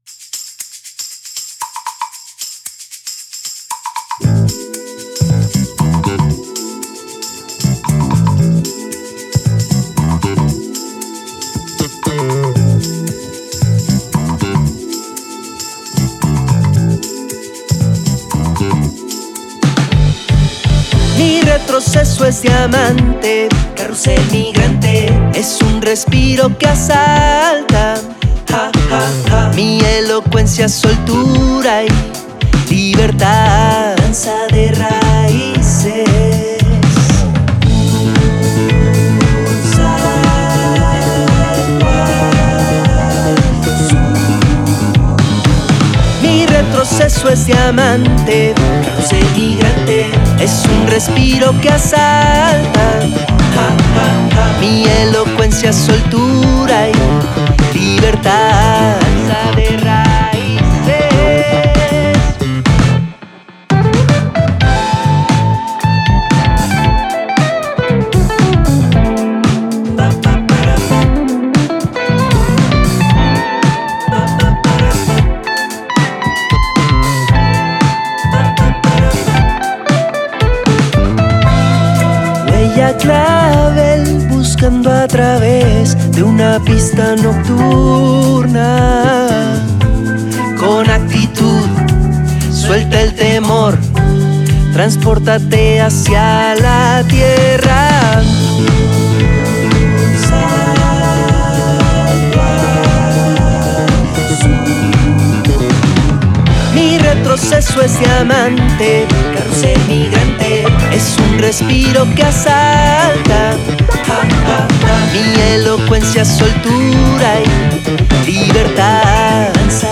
voice & guitars
piano & synth
drums